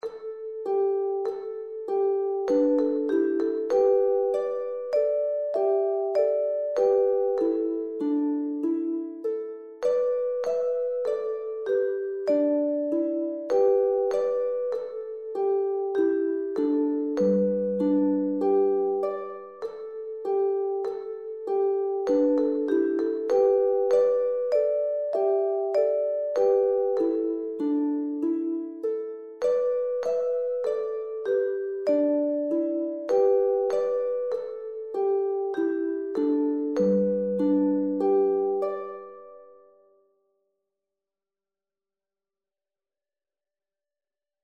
dit liedje is pentatonisch